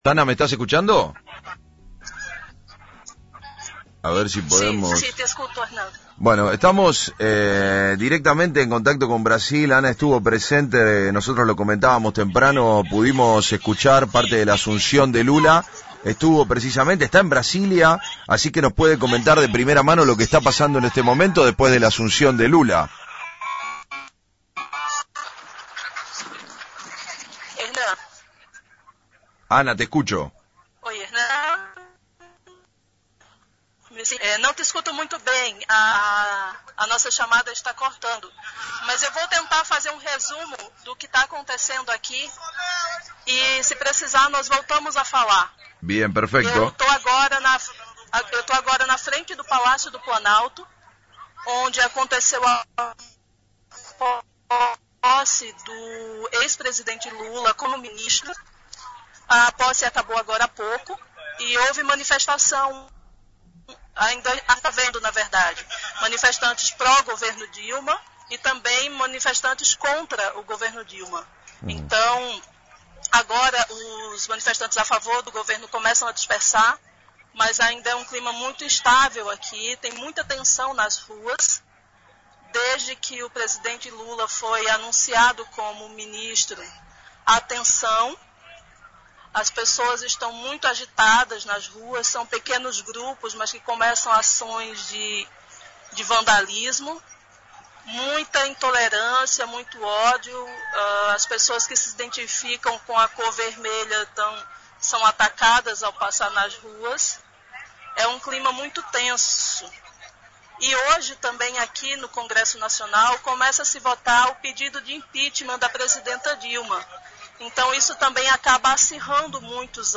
en diálogo con FRECUENCIA ZERO